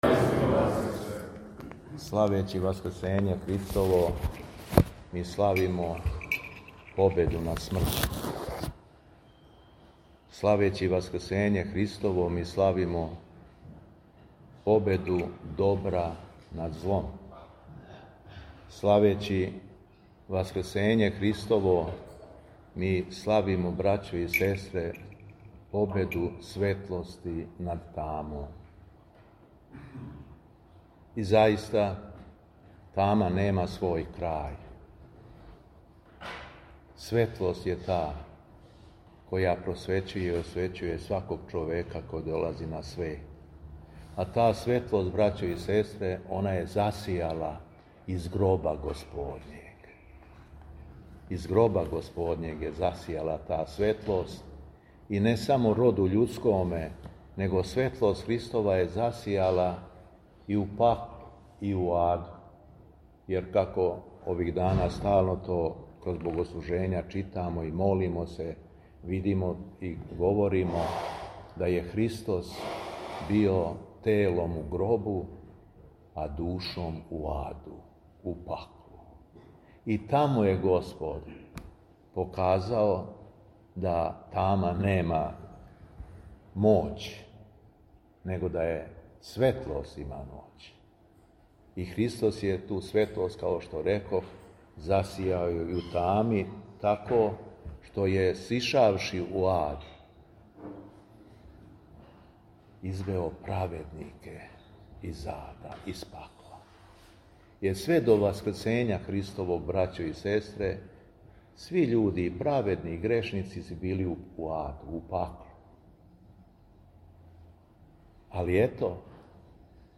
Беседа Његовог Високопреосвештенства Митрополита шумадијског г. Јована
У четвртак 16. априла 2026. године, Његово Високопресвештенство Митрополит шумадијски Г. Јован служио је Свету Литургију у Старој Цркви у Крагујевцу уз саслужење братства овога светога храма.